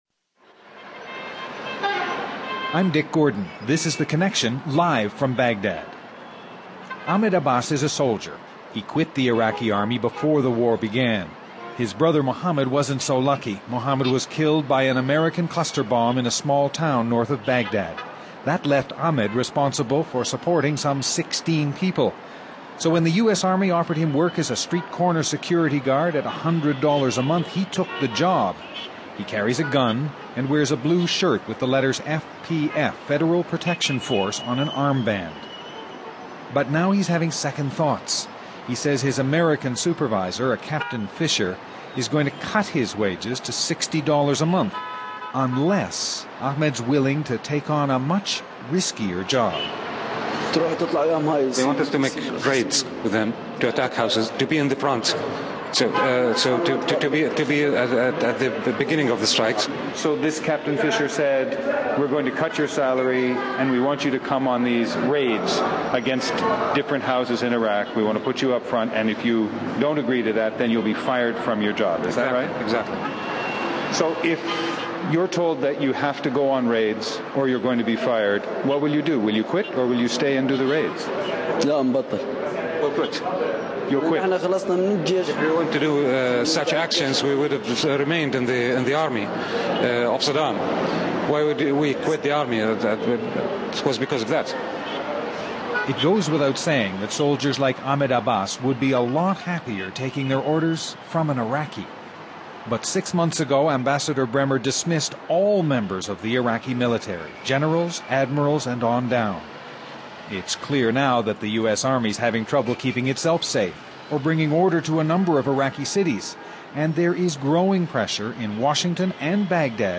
Live from Baghdad